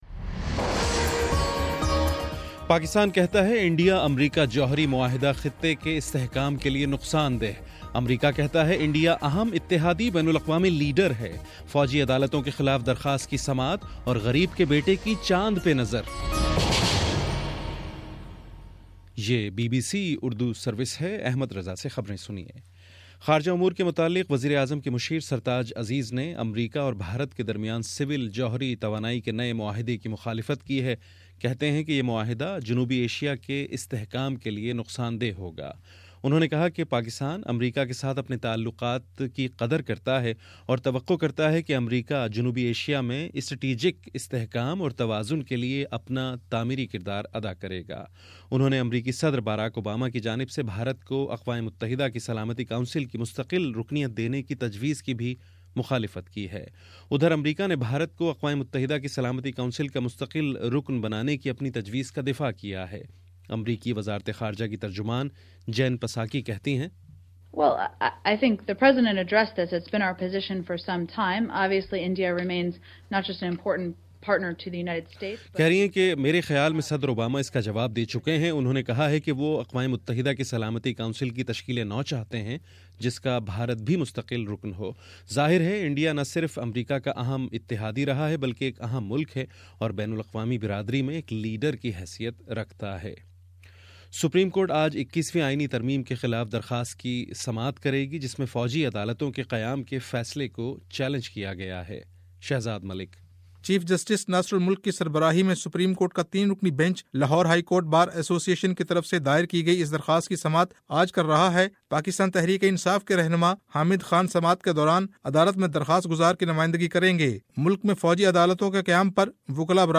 جنوری 28: صبح نو بجے کا نیوز بُلیٹن